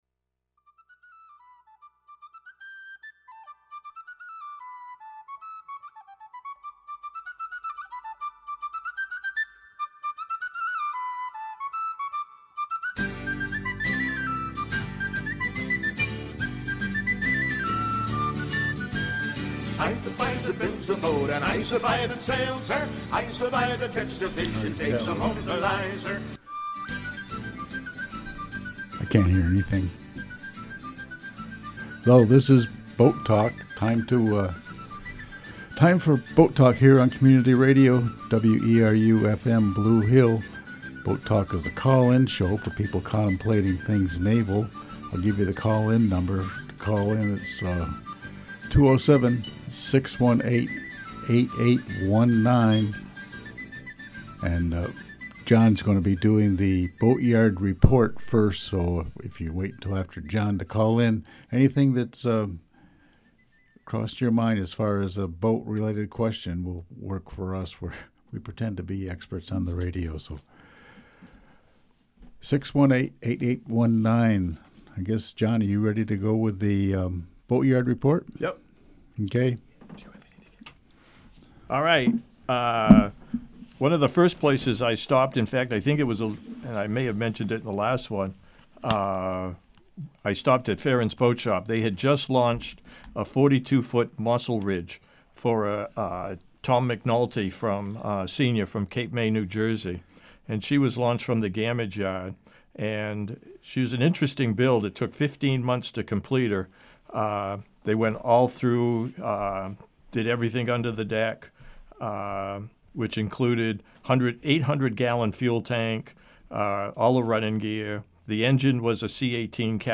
BoatTalk is the call-in show for people contemplating all things naval.